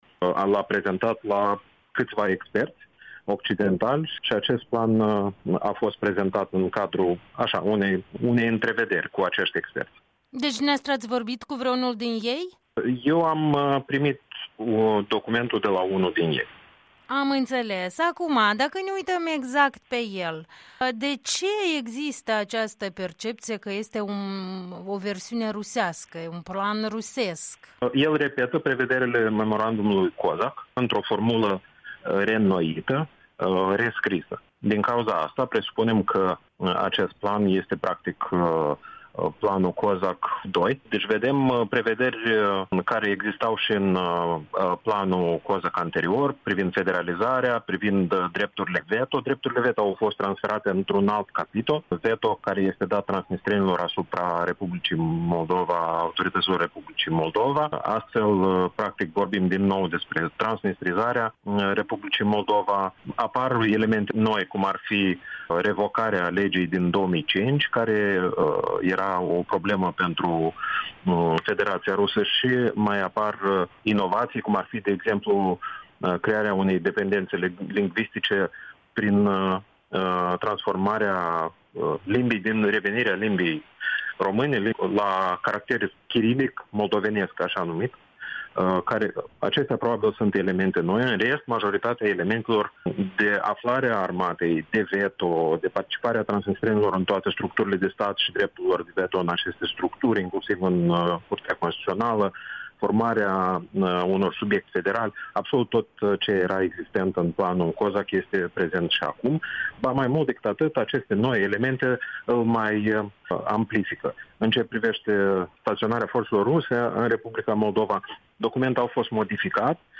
Interviu cu Vlad Lupan